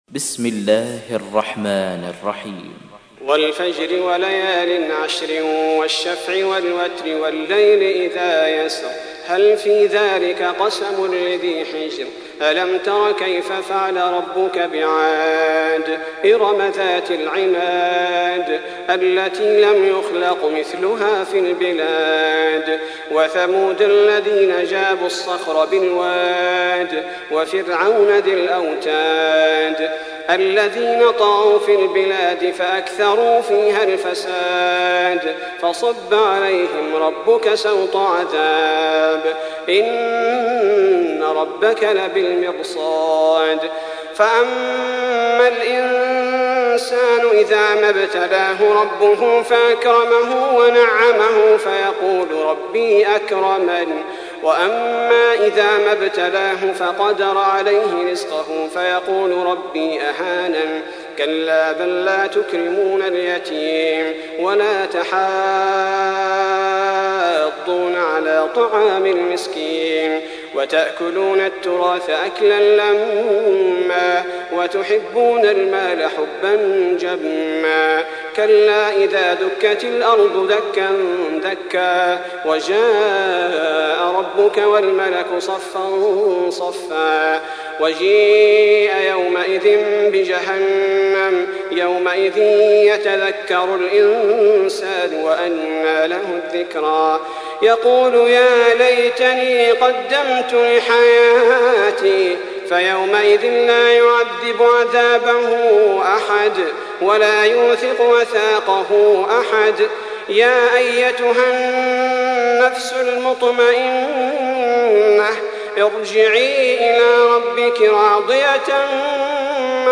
تحميل : 89. سورة الفجر / القارئ صلاح البدير / القرآن الكريم / موقع يا حسين